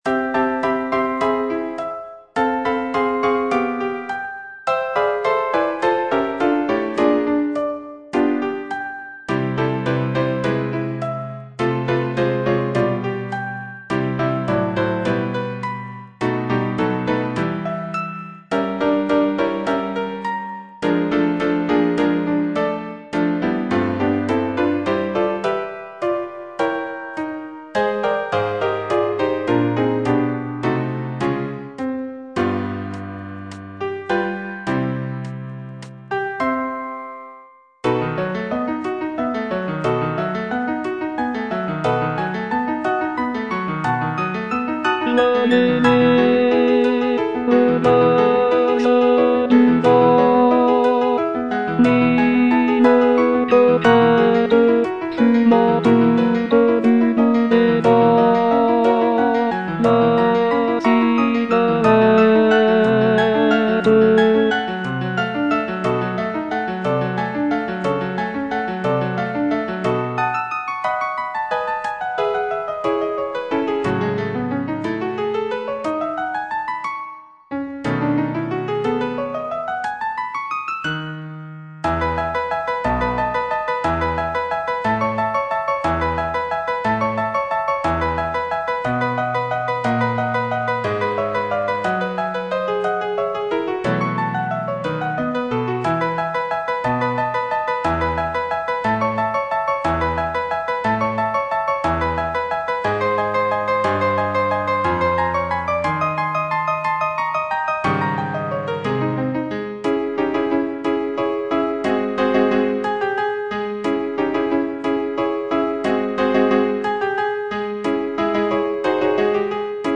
G. BIZET - CHOIRS FROM "CARMEN" Chorus of cigarette-girls - Bass (Voice with metronome) Ads stop: auto-stop